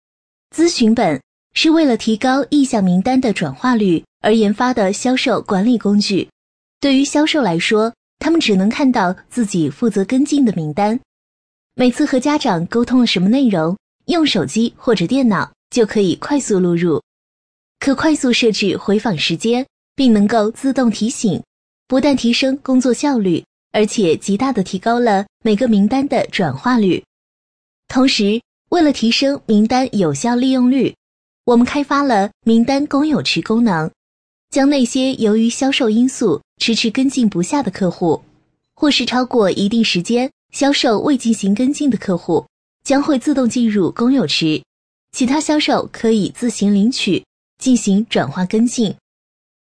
【女50号解说】校宝秀
【女50号解说】校宝秀.mp3